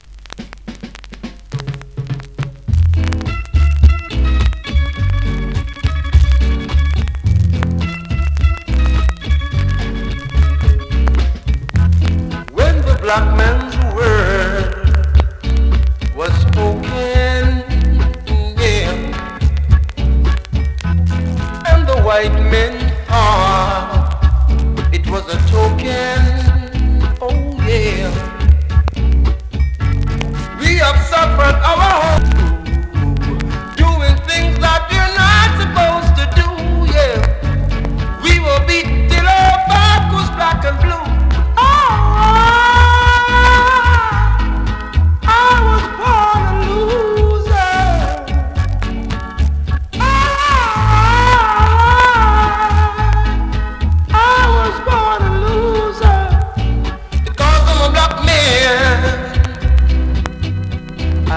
NEW IN!SKA〜REGGAE
(少し音割れ有) コメント名曲!!
スリキズ、ノイズそこそこありますが